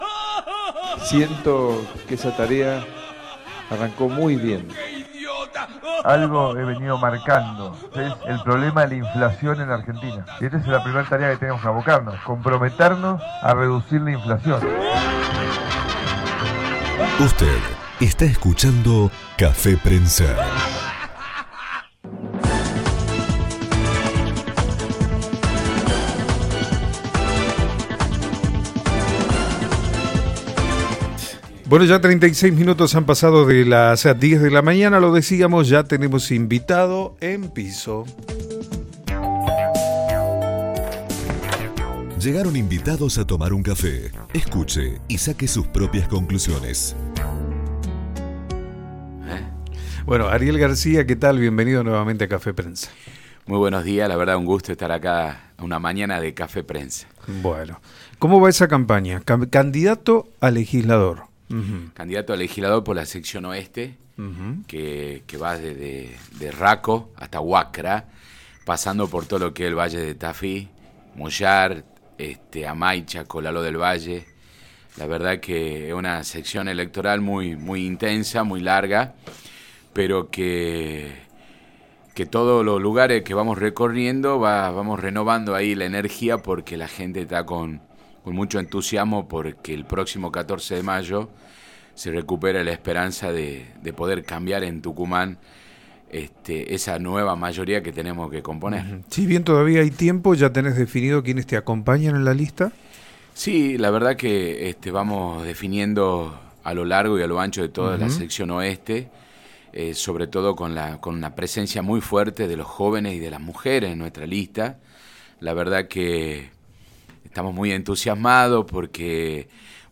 en diálogo con Café Prensa, se refirió a su candidatura y analizó la política local.